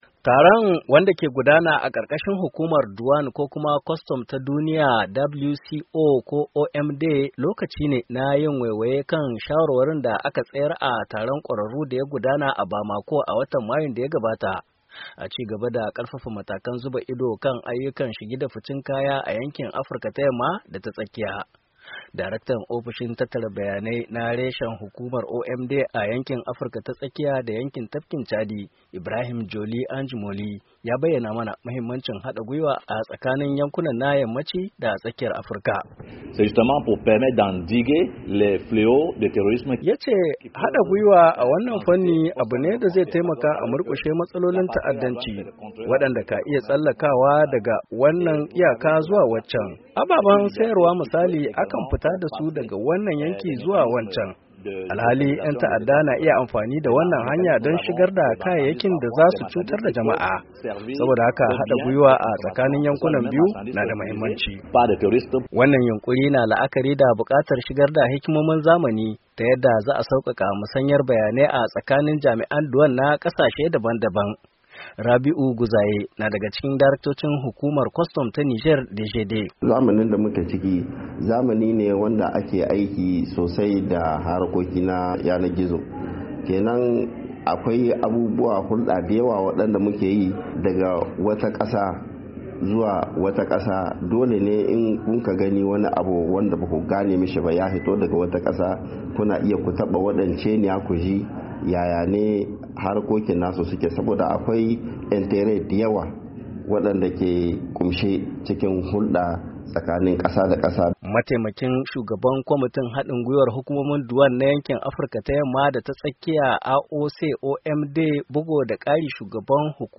NIAMEY, NIGER —